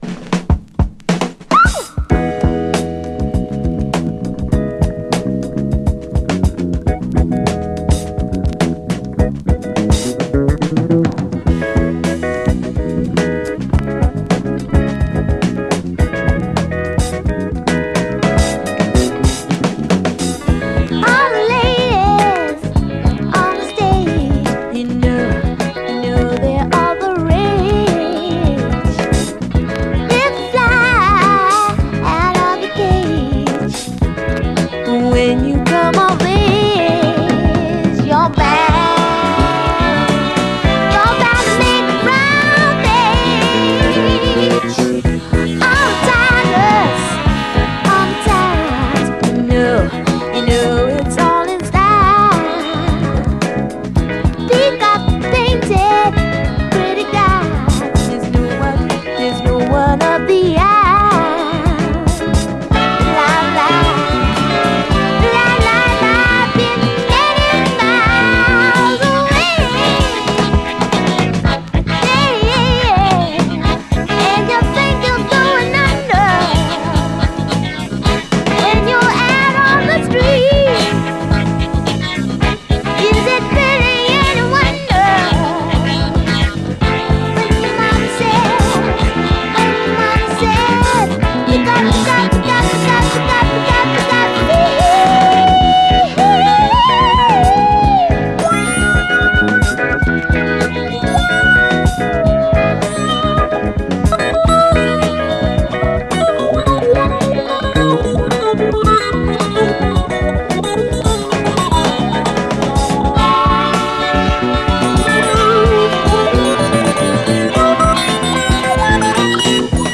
SOUL, 70's～ SOUL, 7INCH
グルーヴィーかつキュートなUK産70’Sラヴリー・ソウル！